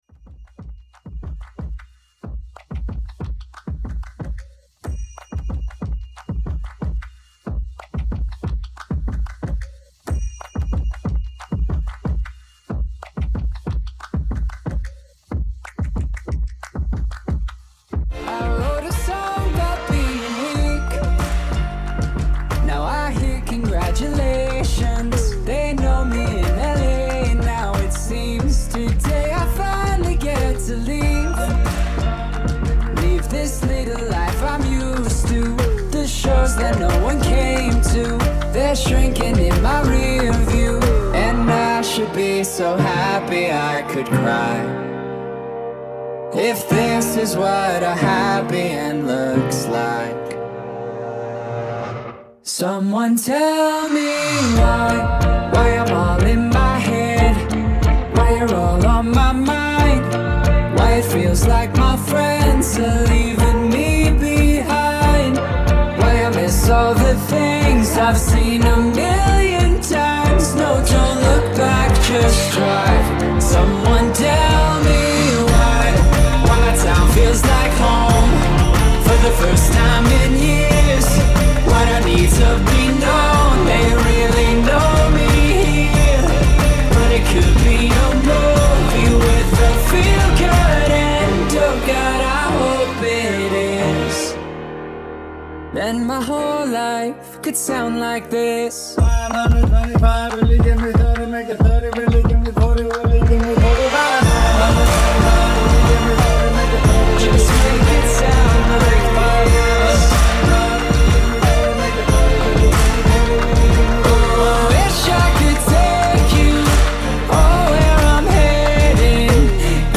A live cocktail hour mix